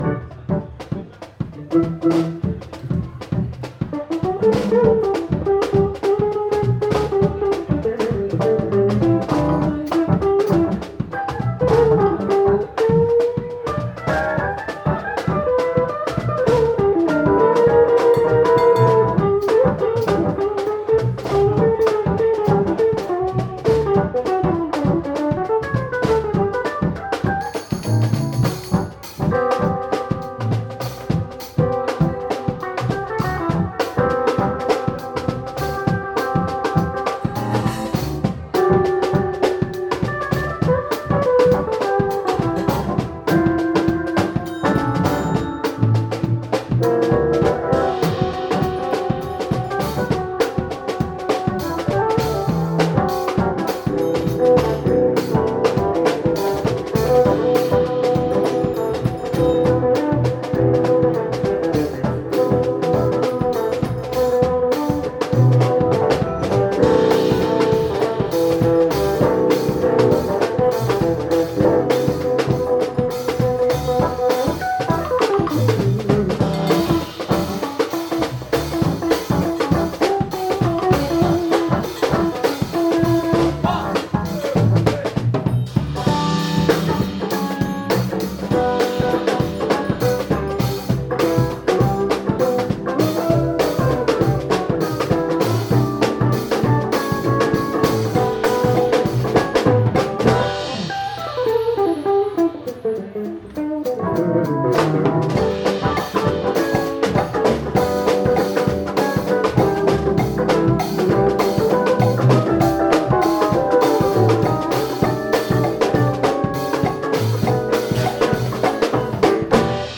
Keys
Guitar
Drums
Bass and percussionists